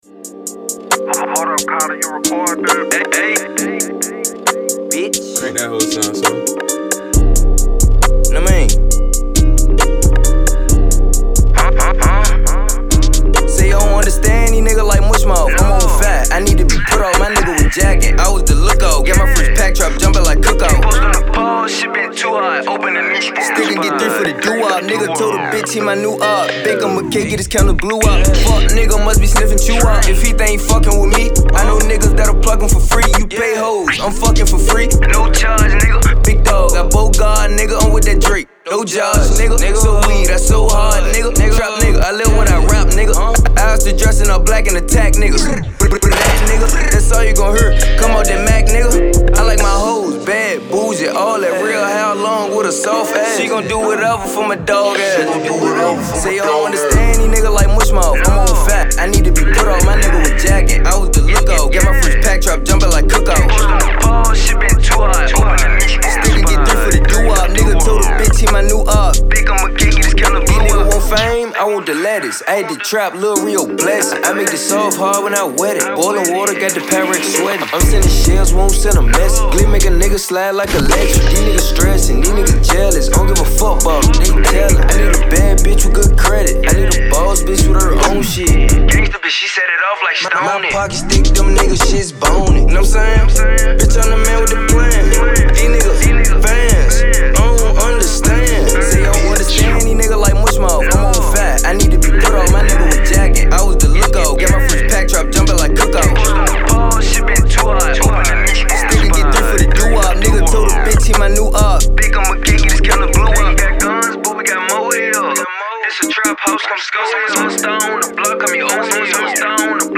Studio recording client - Richmond